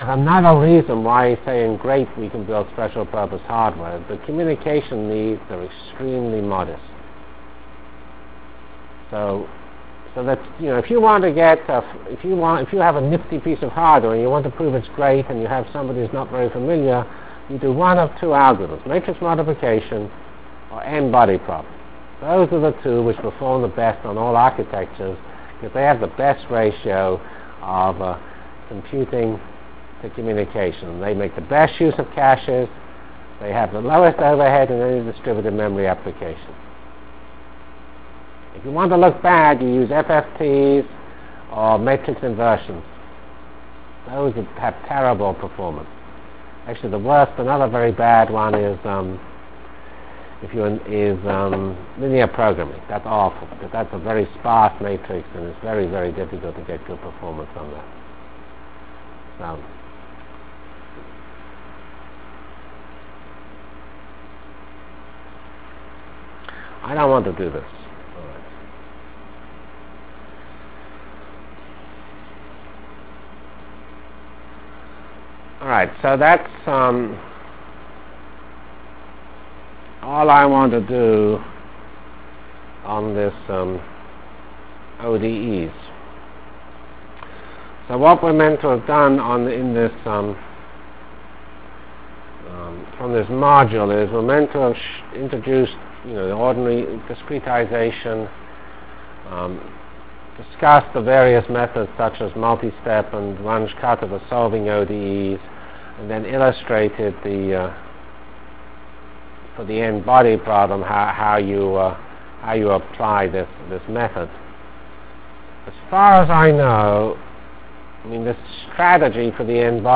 Delivered Lectures of CPS615 Basic Simulation Track for Computational Science -- 15 October 96.